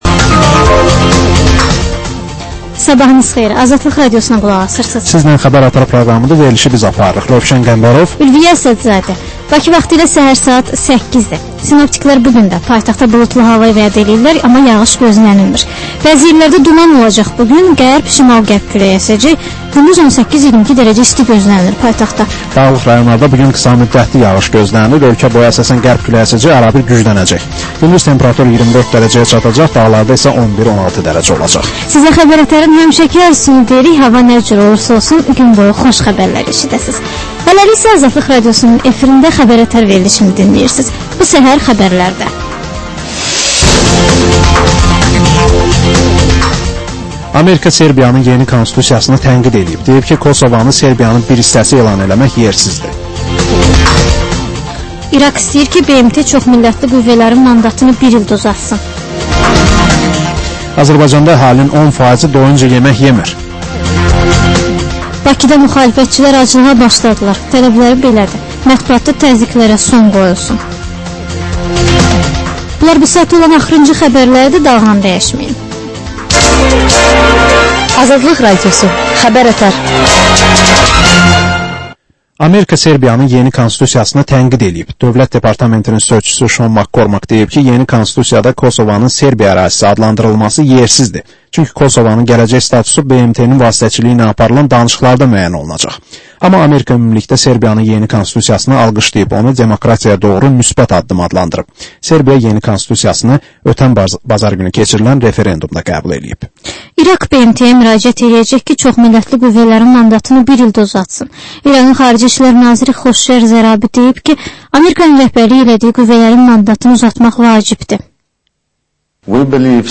Xəbərlər
Səhər-səhər, Xəbər-ətər: xəbərlər, reportajlar, müsahibələr.